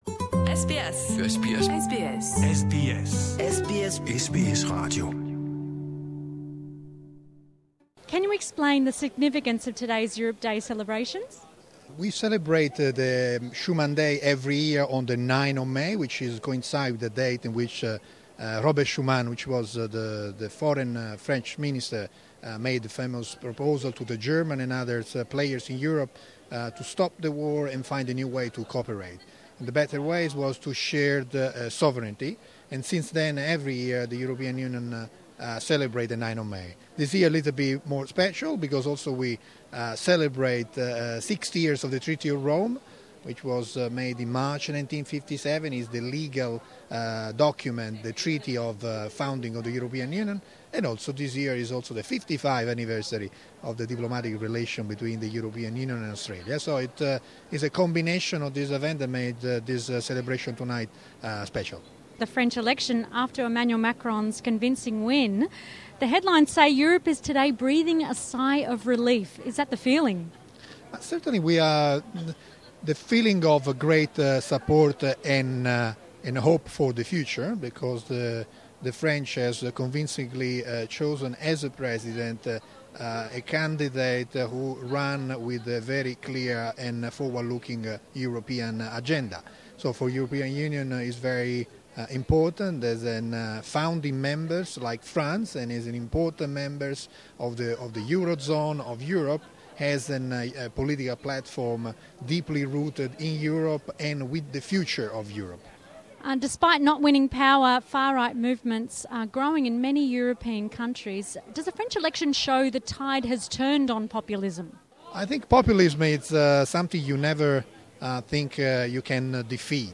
Ambassador Sem Fabrizi is the Head of Delegation of the European Union to Australia and New Zealand. Mr Fabrizi spoke at the Europe Day celebrations in Sydney, where the Sydney Opera House sails were lit up in the blue of the EU logo.